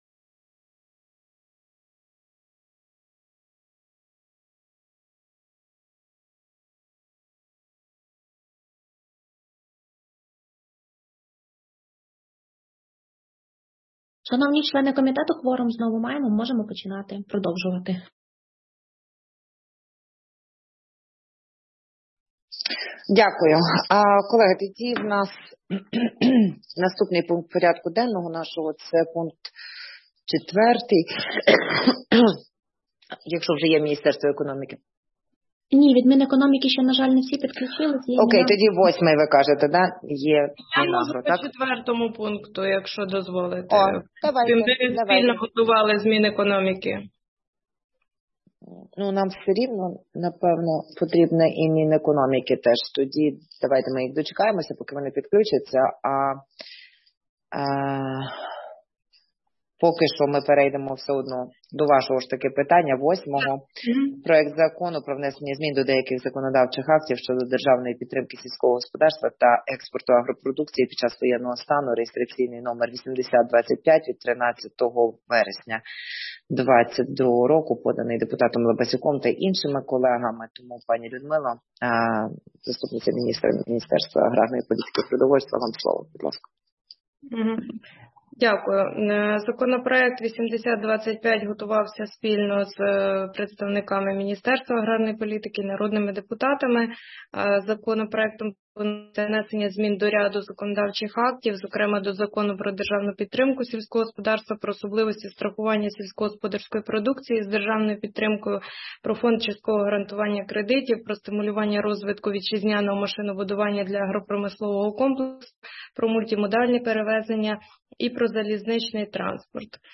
Аудіозапис засідання Комітету 29 листопада 2022 року